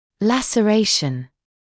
[ˌlæsə’reɪʃn][ˌлэсэ’рэйшн]разрыв; рваная рана